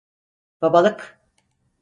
Pronunciado como (IPA)
[babaɫɯk]